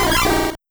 Cri de Qwilfish dans Pokémon Or et Argent.